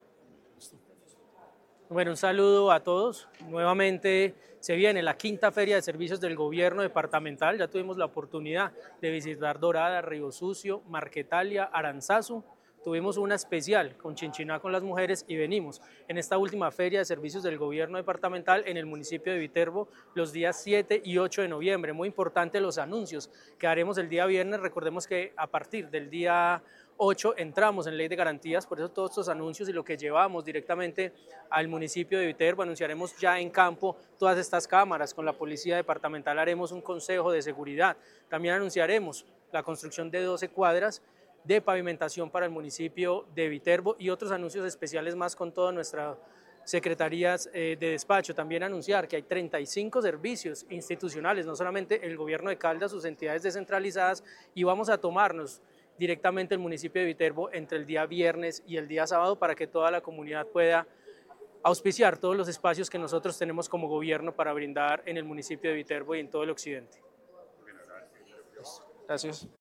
Ronald Fabián Bonilla, secretario privado de la Gobernación de Caldas.